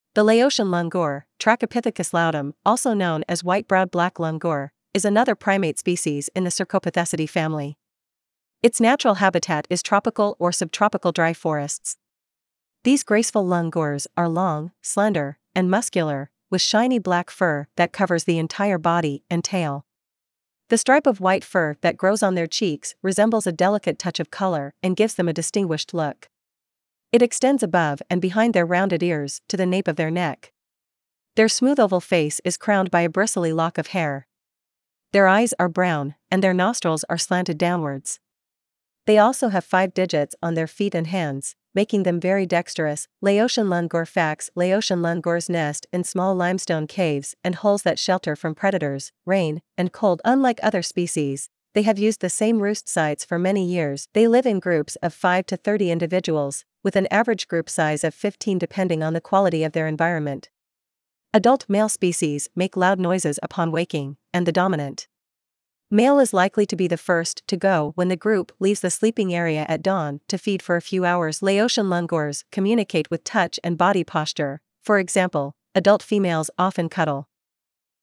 Laotian Langur
• Adult male species make loud noises upon waking, and the dominant male is likely to be the first to go when the group leaves the sleeping area at dawn to feed for a few hours.
Laotian-langur.mp3